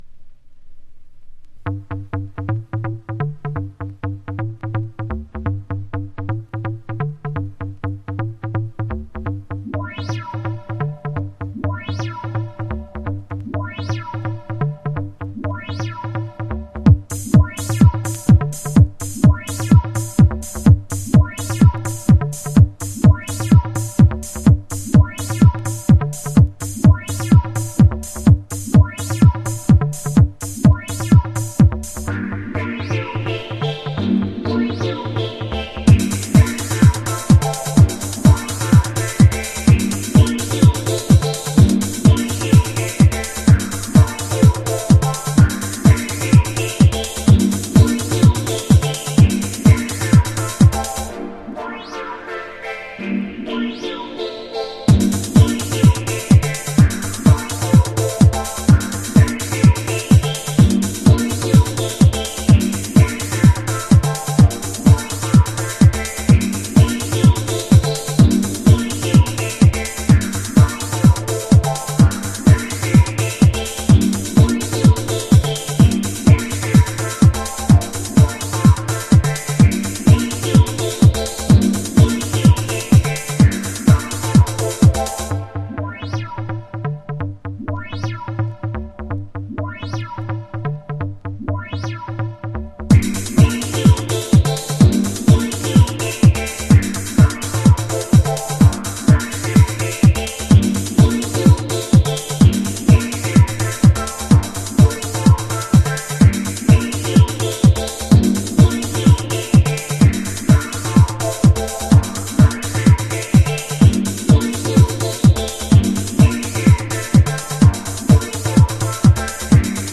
House / Techno
イタロなベースにウワ音とビートのソワソワは90'SのUKハウスのアノ感じが滲み出てるB2がオススメ